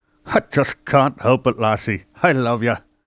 Scotty in love (Impersonated)
Ace Ventura impersonates Scotty